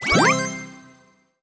collect_item_05.wav